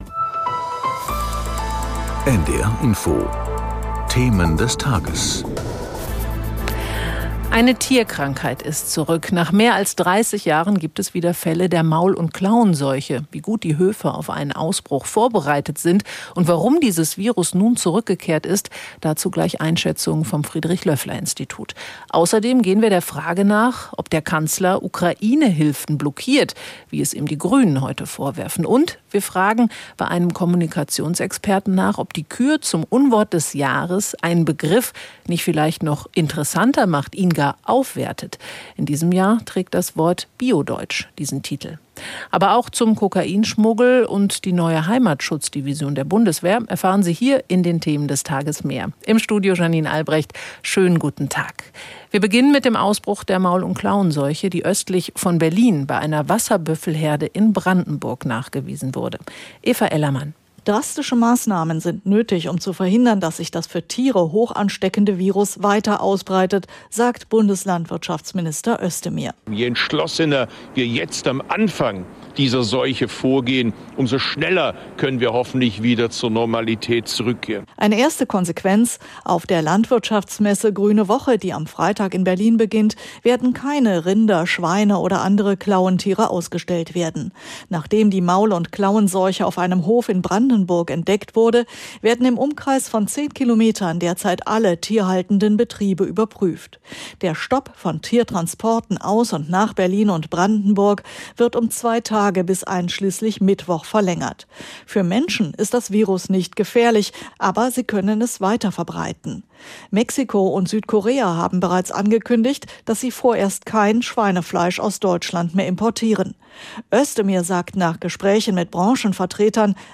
In Gesprächen mit Korrespondenten und Interviews mit Experten oder Politikern.